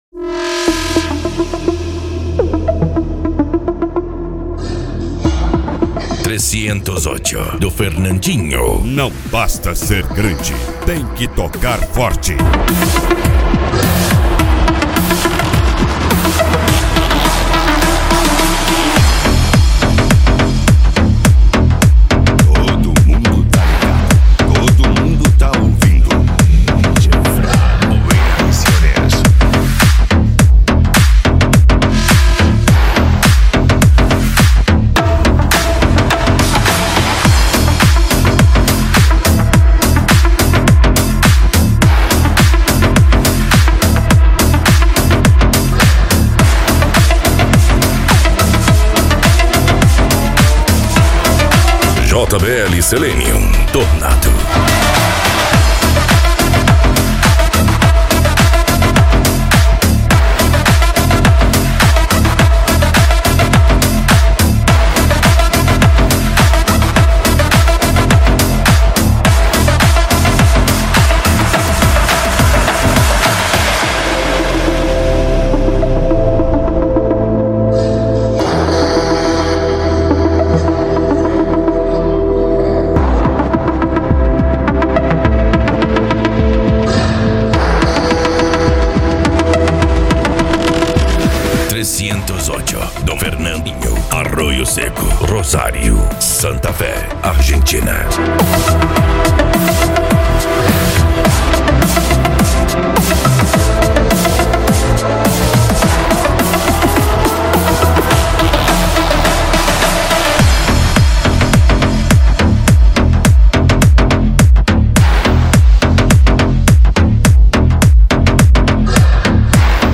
Bass
Cumbia
Funk
PANCADÃO
Psy Trance
Remix